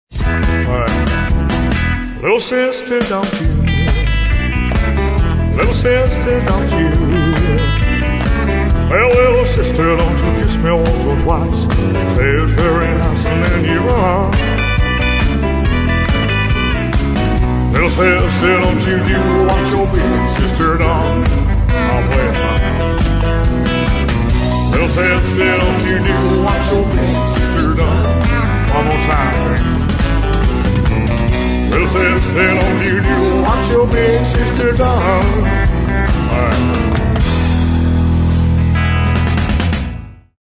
Male
My normal voice is conversational, an articulate Australian in instructional videos but as an impersonator, I specialize in character voices, accents and dialects, sounding like famous speaking and singing voices from Elvis to Sean Connery.
Impersonations Elvis Presley
0521Elvis_Impersonations.mp3